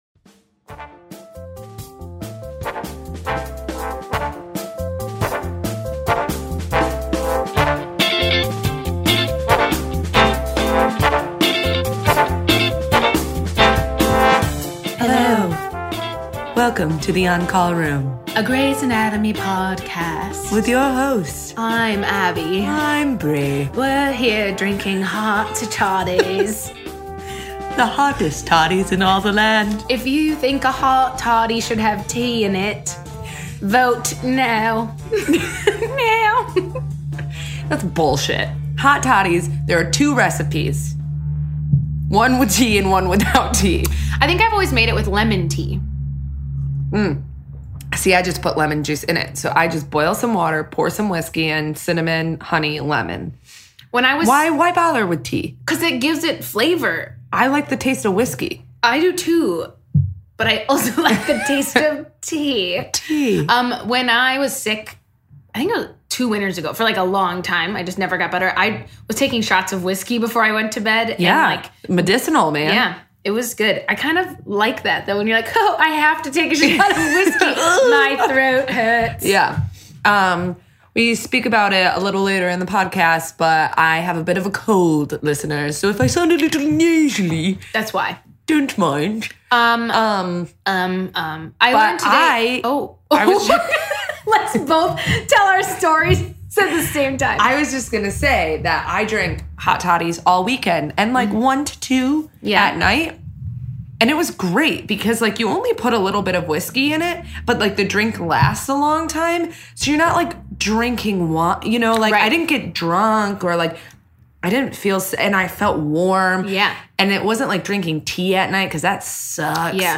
so don't mind her nasally voice.